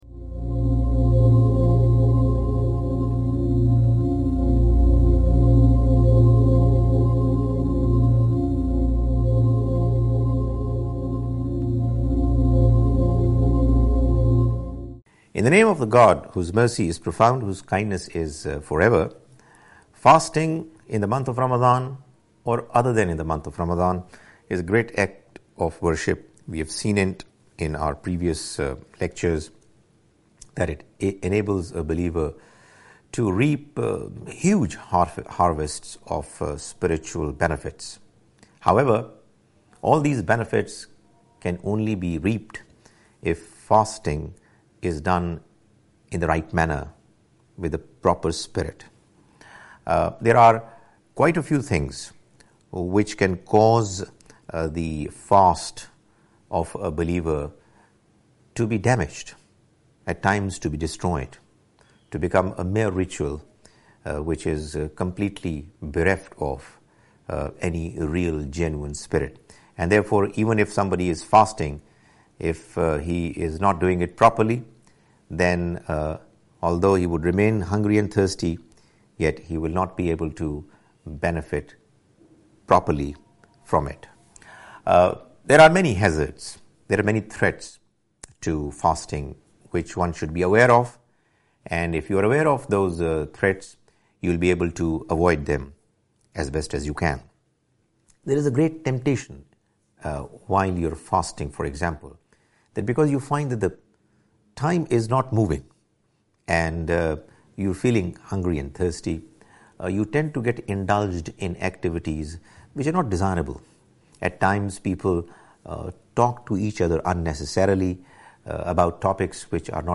A short talk